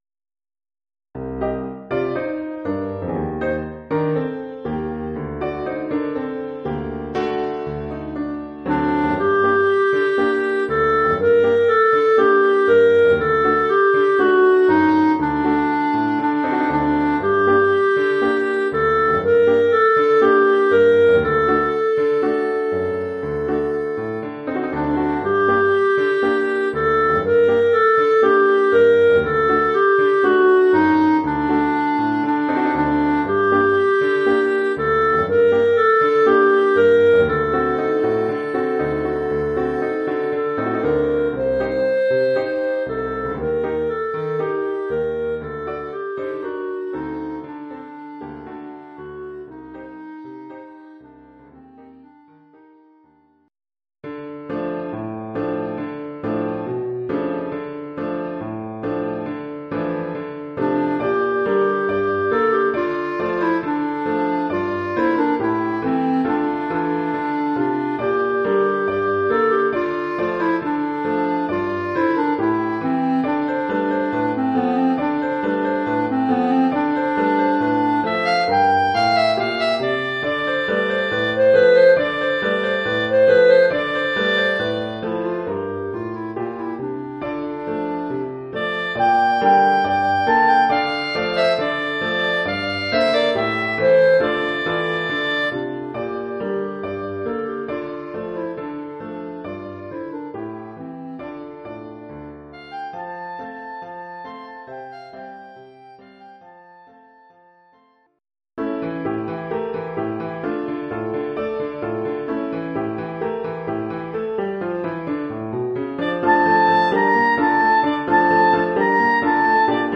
traditionnels yiddish".
Oeuvre pour clarinette et piano.